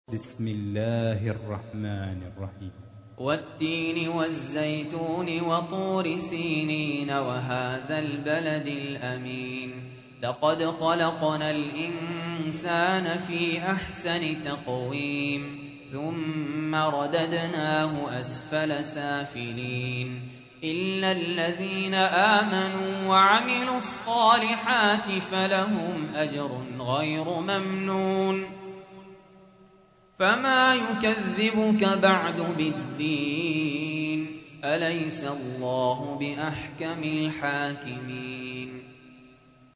تحميل : 95. سورة التين / القارئ سهل ياسين / القرآن الكريم / موقع يا حسين